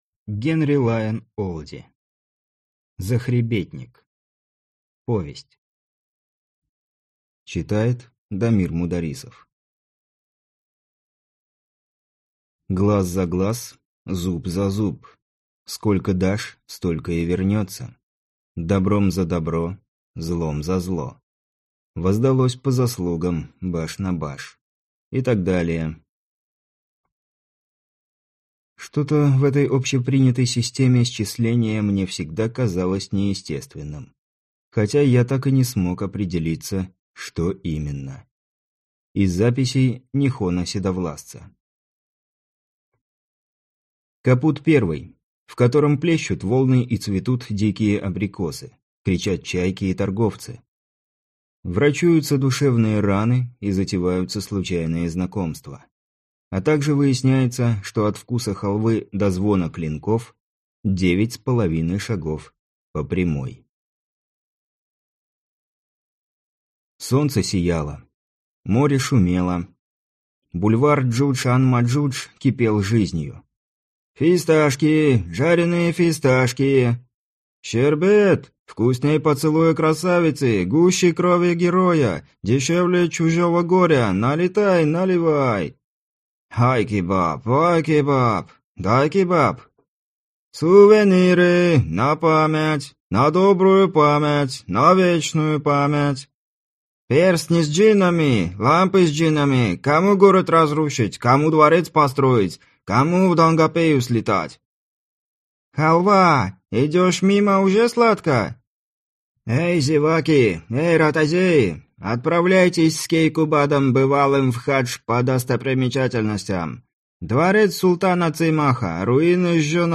Аудиокнига Захребетник | Библиотека аудиокниг